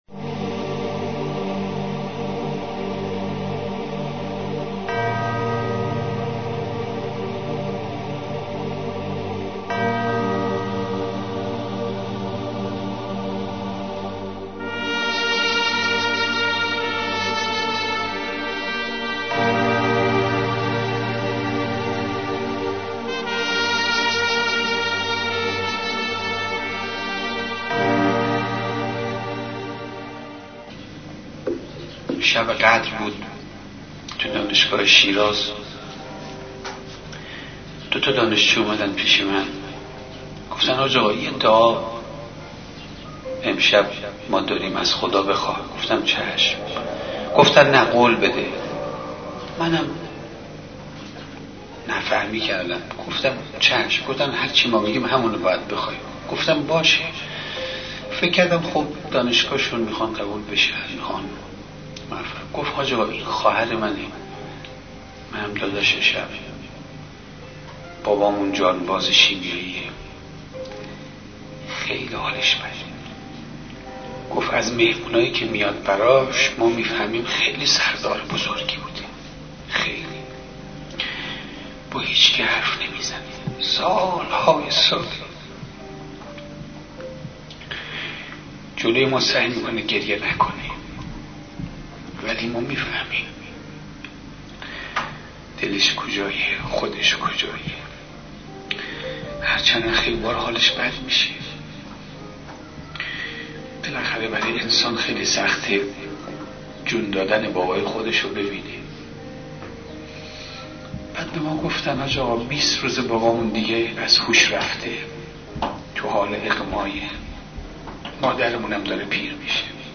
صوت روایتگری
ravayatgari166.mp3